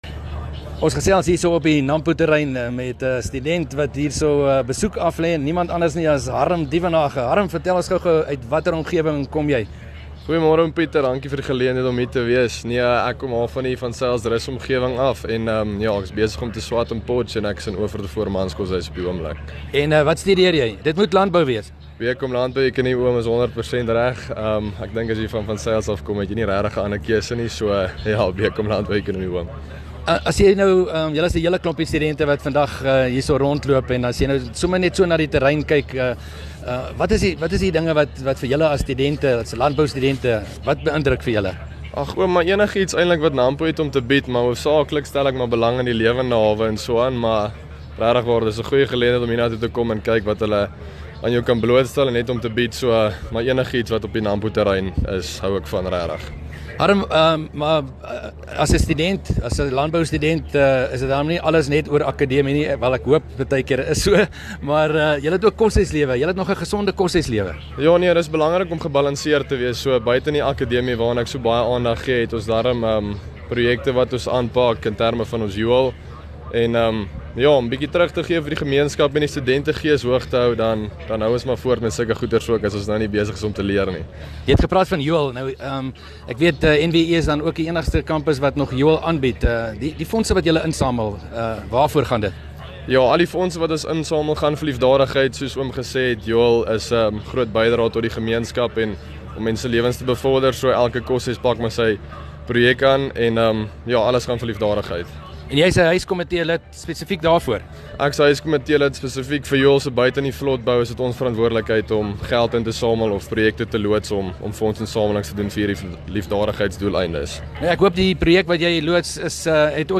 18 May PM gesels met ‘n landboustudent uit die Kalahari op die Nampopark-terrein